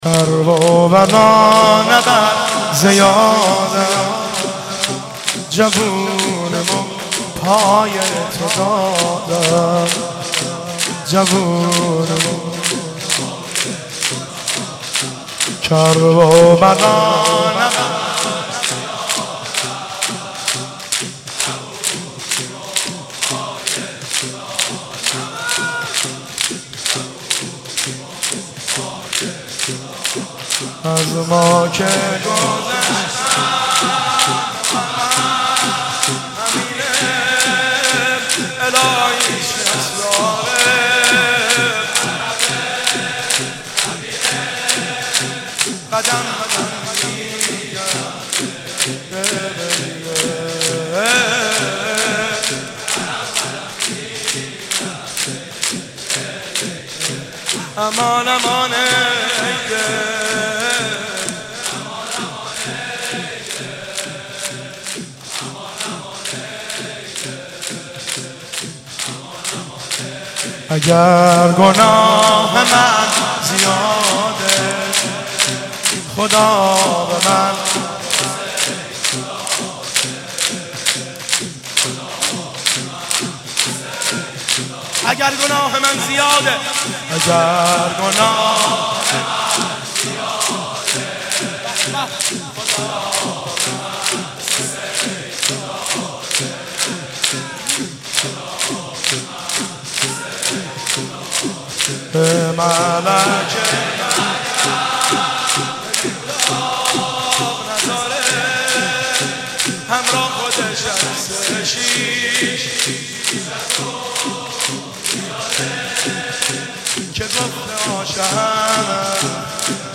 شب هفتم محرم95/ هیئت غریب مدینه امیر کلا (بابل)
زمزمه و روضه
شعرخوانی